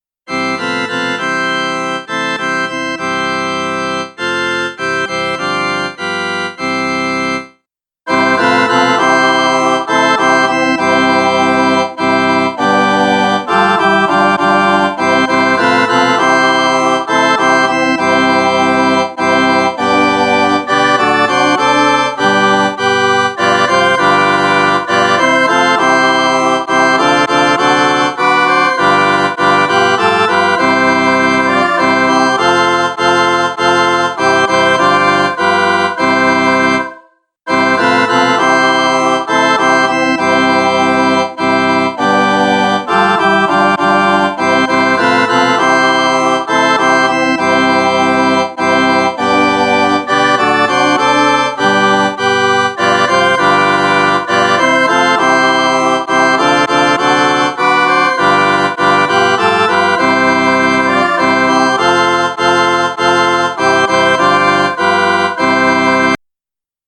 base musicale